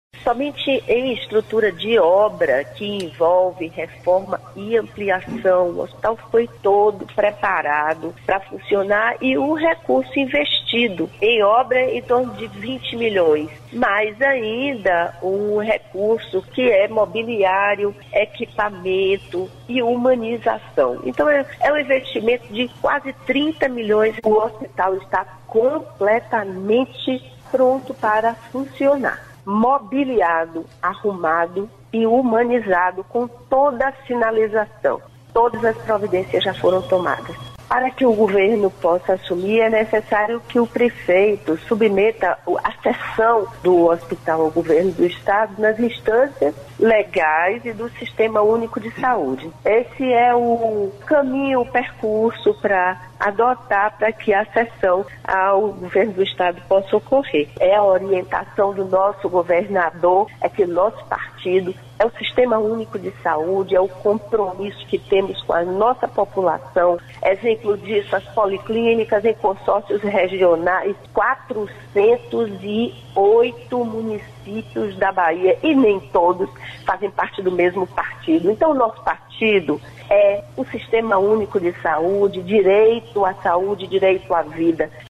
Sonora da secretária da Saúde da Bahia, Adélia Pinheiro: